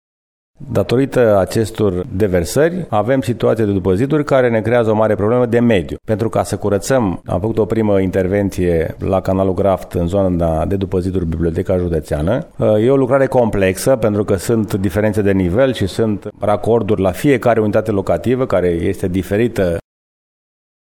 Primarul municipiului Brașov, George Scripcaru: